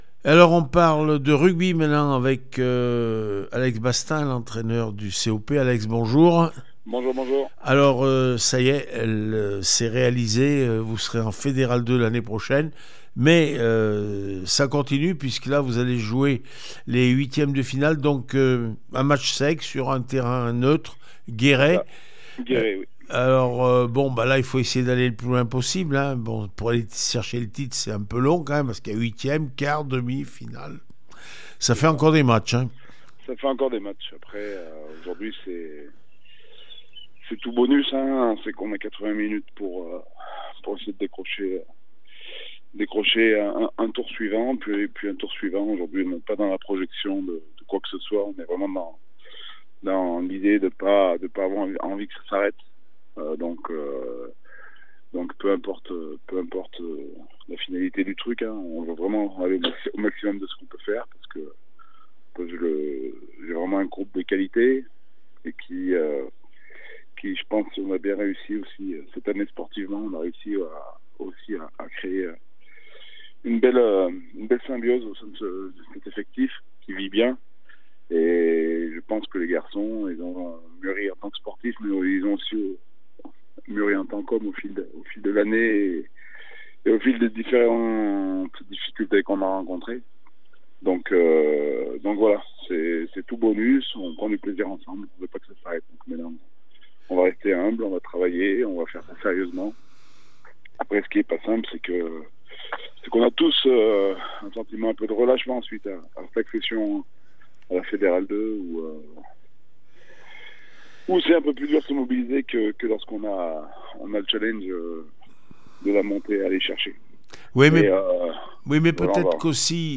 27 mai 2023   1 - Sport, 1 - Vos interviews